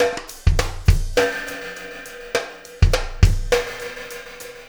Pulsar Beat 08.wav